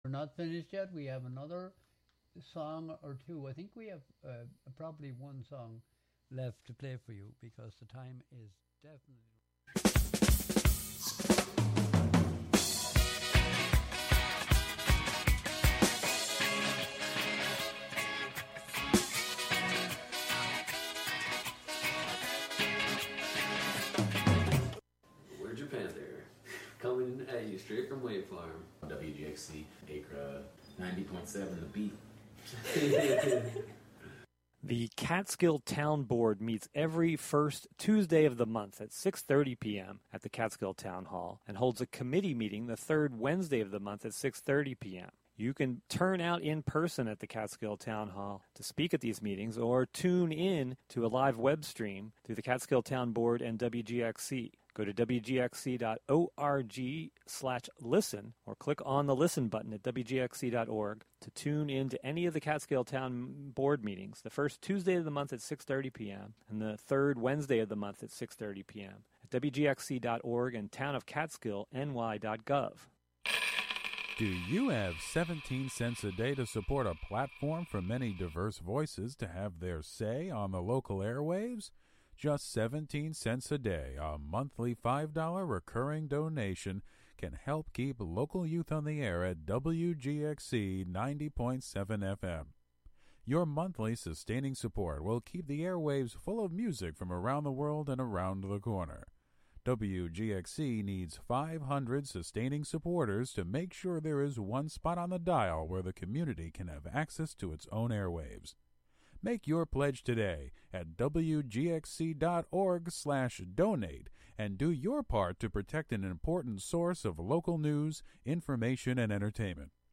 R&B hits of the 1950s and 1960s
commentary